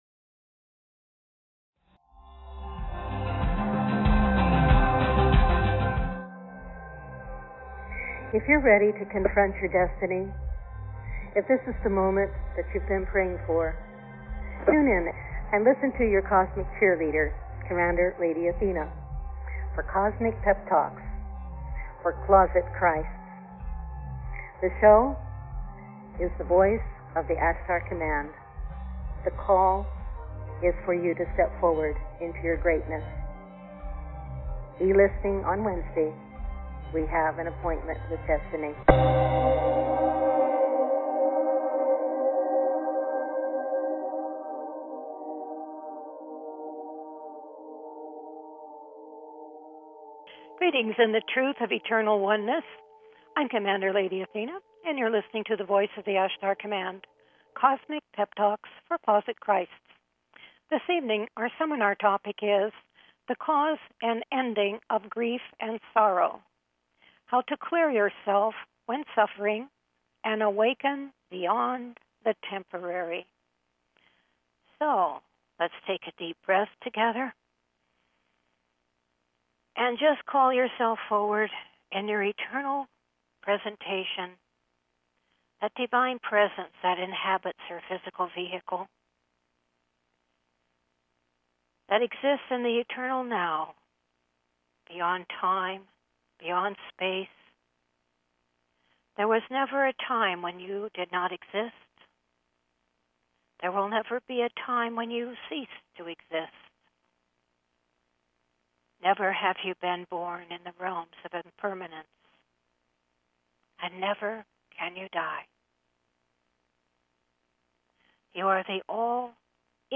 THE VOICE OF THE ASHTAR COMMAND radio shows serve to empower you in acquiring consciousness reference points for your present level of awareness.
Various experiential processes, meditations and teachings evoke your Divine knowing and Identity, drawing you into deeper com